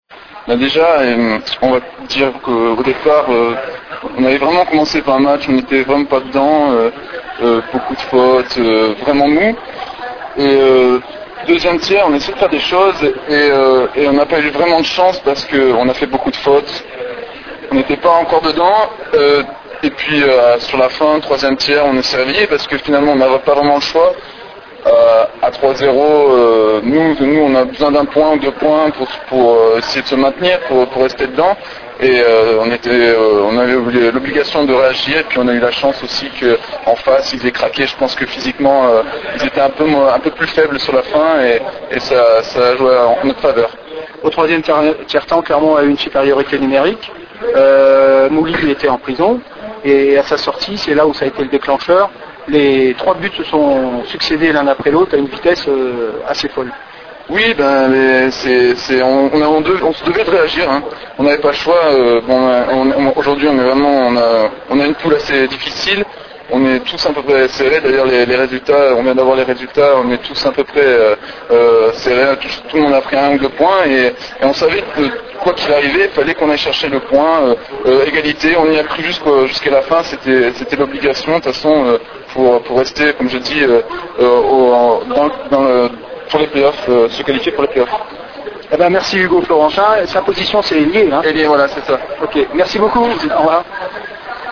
Réaction d'après match